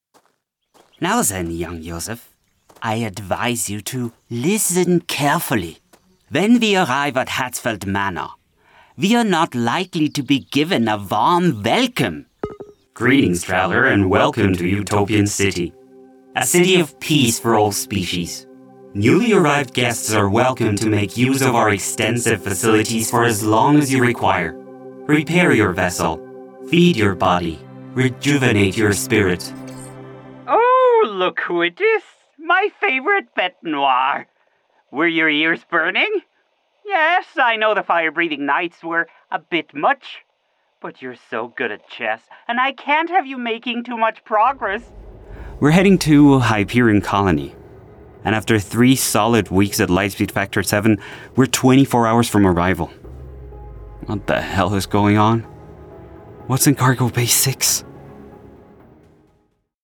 Male
Authoritative, Character, Confident, Friendly, Versatile
Voice reels
Microphone: Austrian Audio OC18, Shure SM7B, Austrian Audio CC8, t.bone RM 700